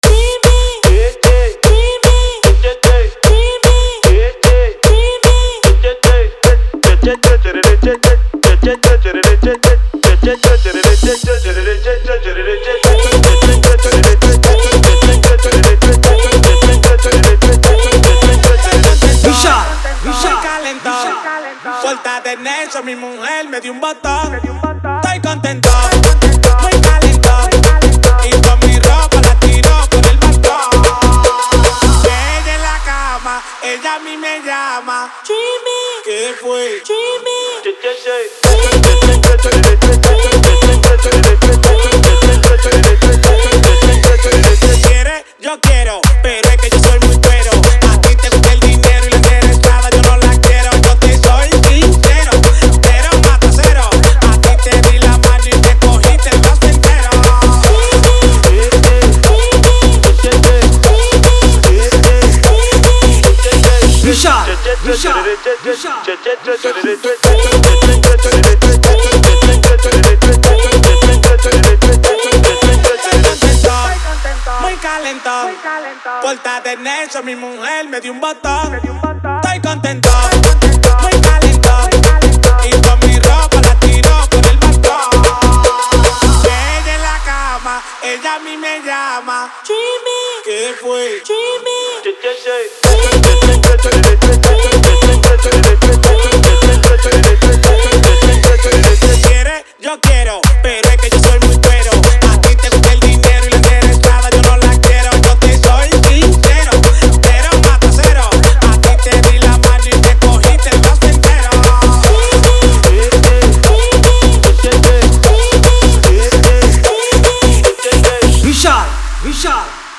• Category:Marathi Single